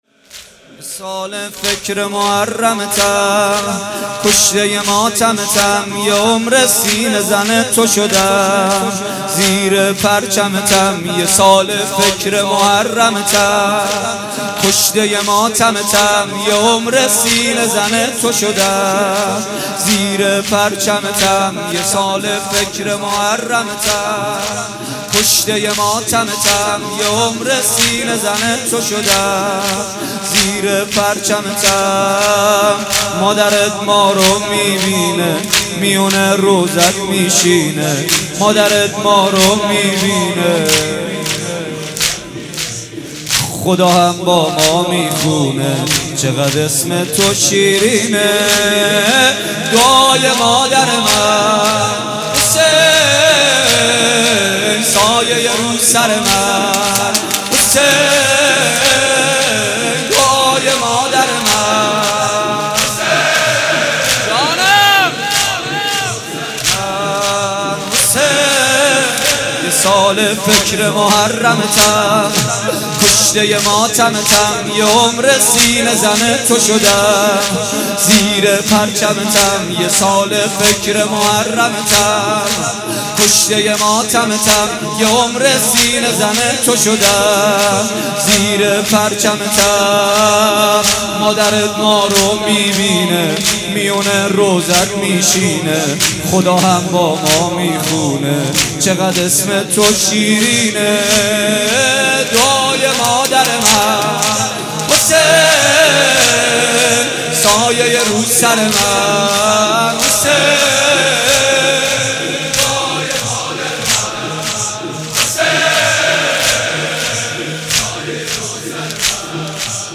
مداحی محمد حسین پویانفر شب اول محرم الحرام 1438 صوت - تسنیم
صوت مداحی محمدحسین پویانفر شب اول محرم در ریحانه النبی سلام‌الله علیها منتشر می شود.